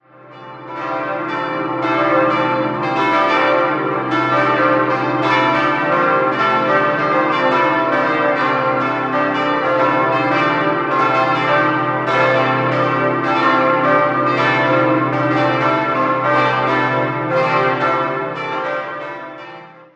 5-stimmiges ausgefülltes Salve-Regina-Geläute: c'-d'-e'-g'-a'
Alle Glocken wurden 1965 von der Gießerei Mabilon (Saarburg) hergestellt.